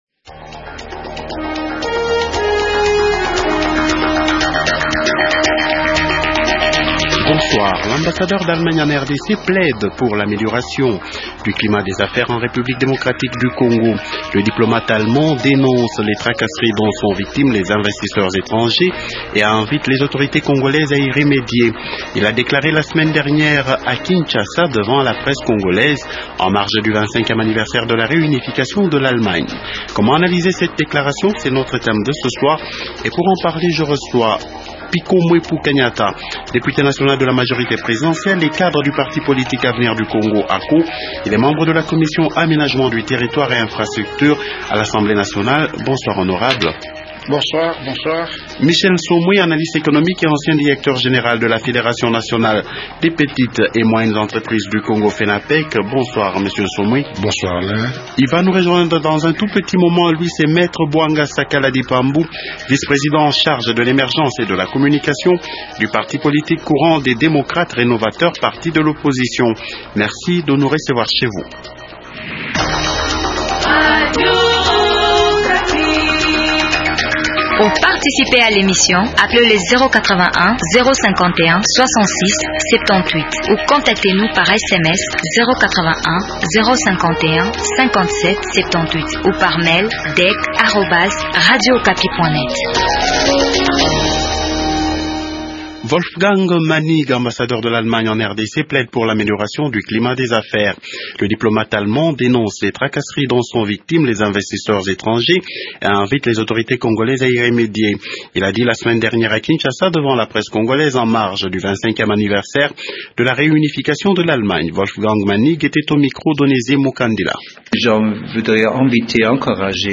Les débatteurs de ce soir sont :